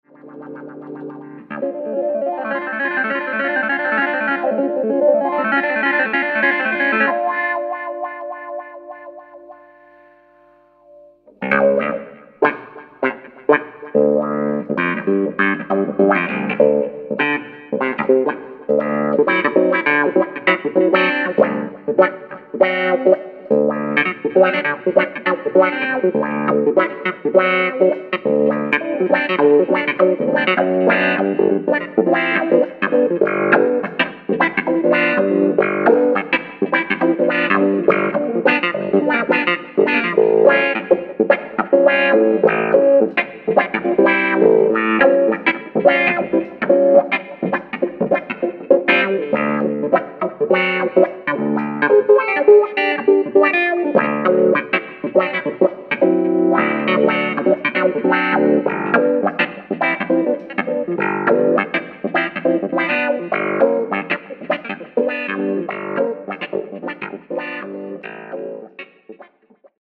Clavinet
clavinet-E7-Droomtent.mp3